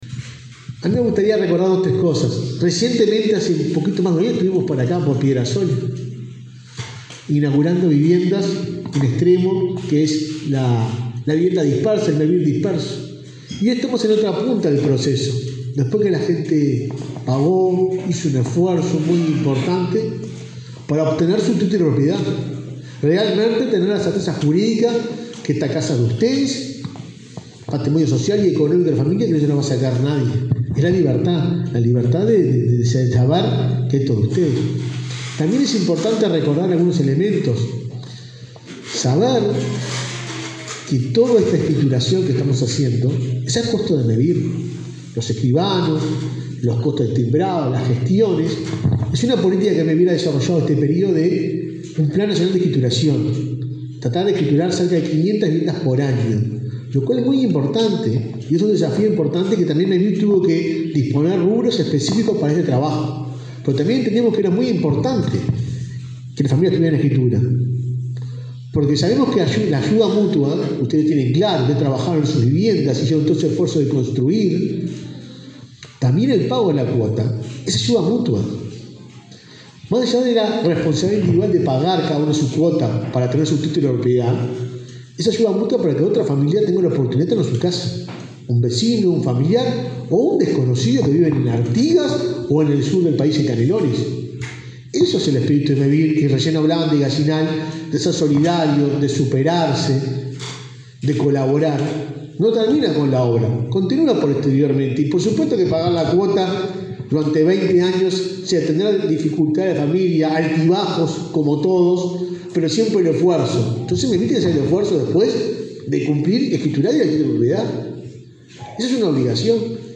Palabras del presidente de Mevir, Juan Pablo Delgado
Palabras del presidente de Mevir, Juan Pablo Delgado 22/06/2023 Compartir Facebook X Copiar enlace WhatsApp LinkedIn Este jueves 22, Mevir realizó un acto de escrituras colectivas de viviendas para 24 familias en Piedra Sola, departamento de Tacuarembó, al que asistió el presidente del organismo, Juan Pablo Delgado.